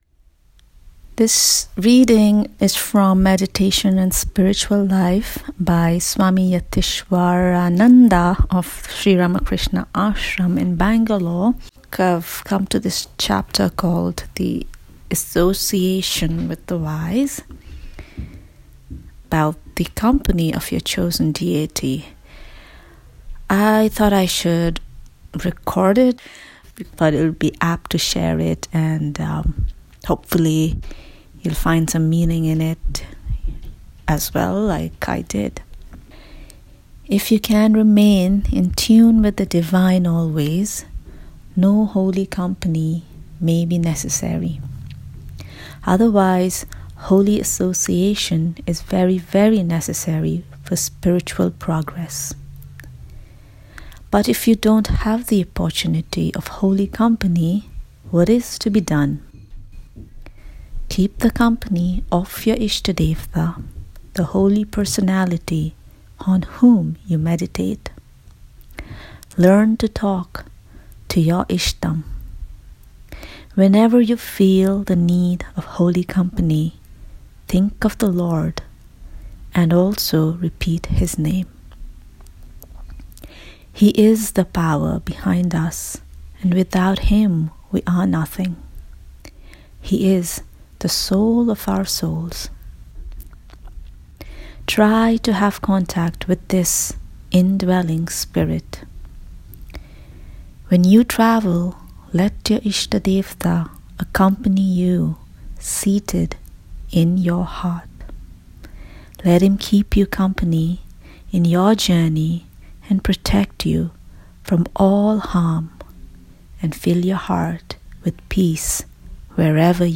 Meditation in Spiritual Life, a morning reading
a reading from Swami Adiswarananda's book